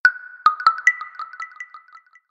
Huawei Bildirim Sesleri